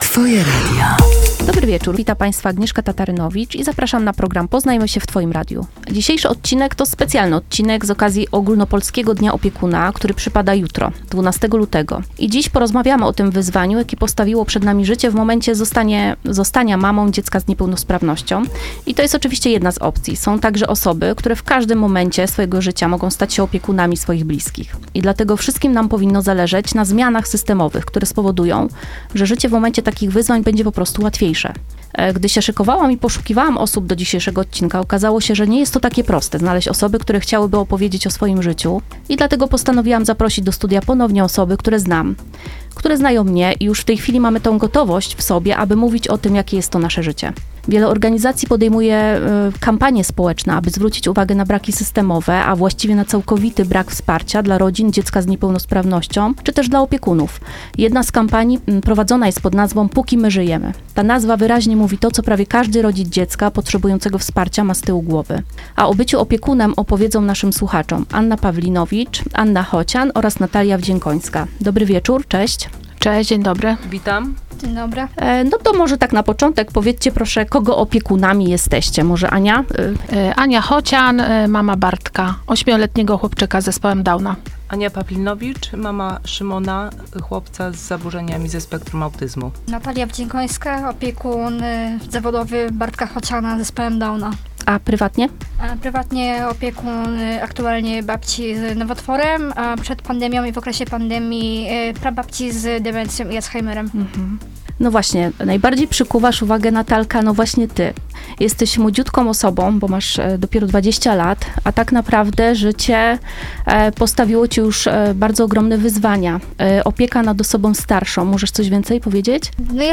W szczerej rozmowie podzielą się swoimi doświadczeniami, opowiedzą, jak wygląda ich życie i podsuną cenne wskazówki, jak radzić sobie w tej niezwykle odpowiedzialnej roli